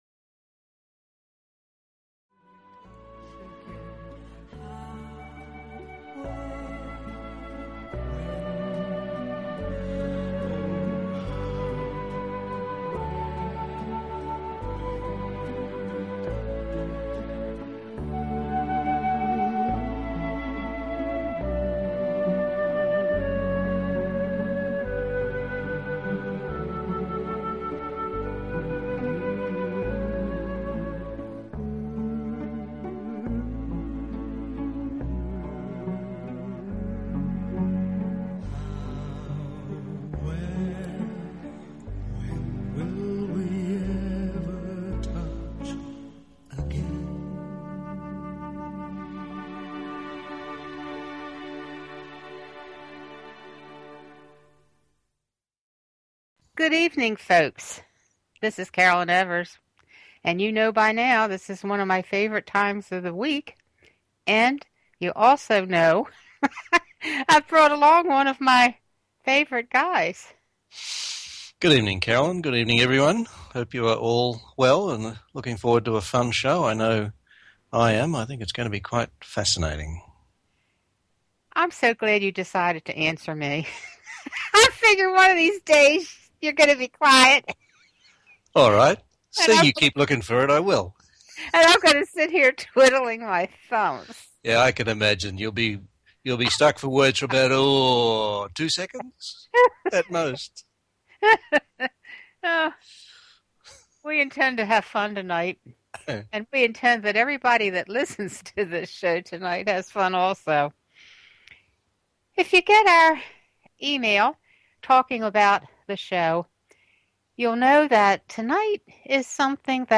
Interviews with Archangel Schmiel and the Earth Guardians